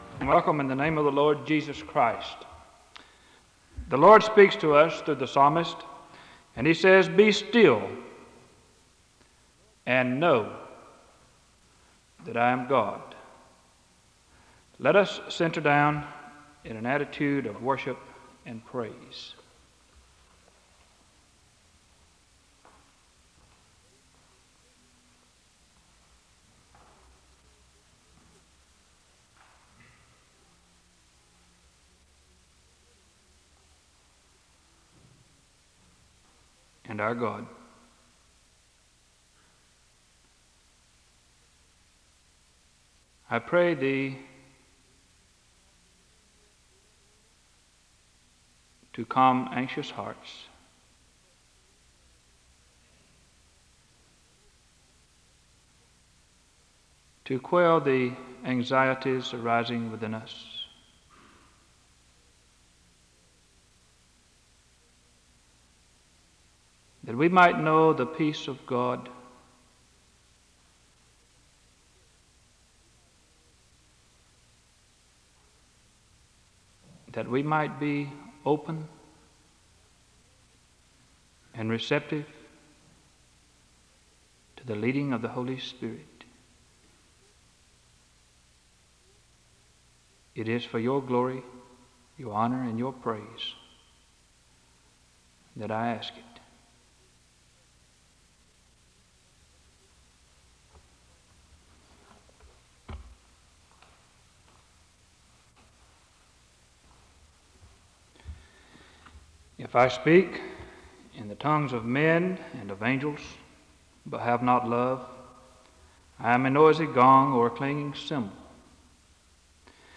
SEBTS Chapel
The recording ends with a benediction (4:04-4:34).
• Wake Forest (N.C.)